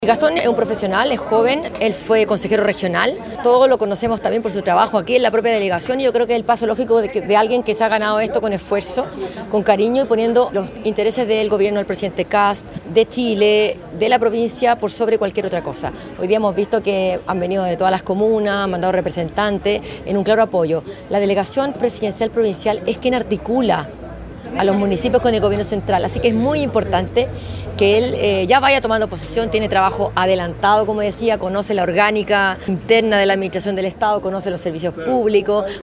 CUNA-ALCALDESA-GARATE-NUEVO-DELEGADO-.mp3.mp3